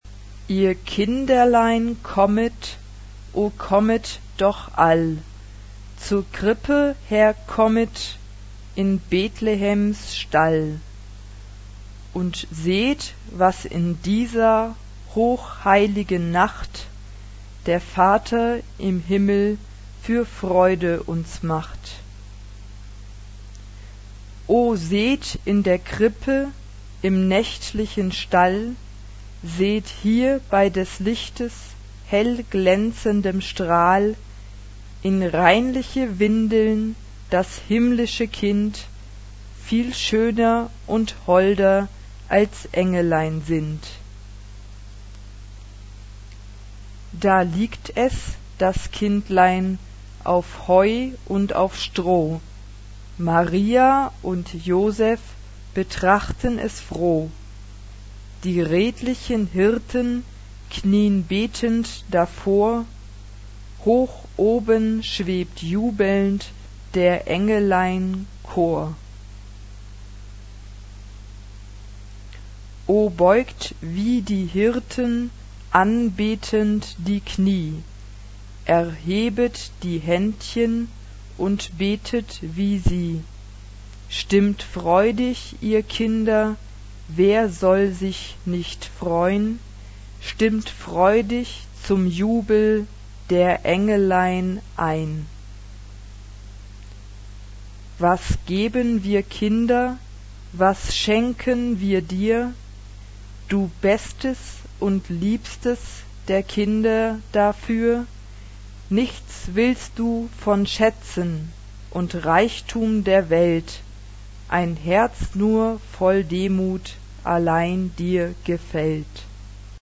SA (2 Kinderchor Stimmen) ; Partitur.
Weihnachtslied. traditionell.
Instrumente: Klavier (1)
Tonart(en): F-Dur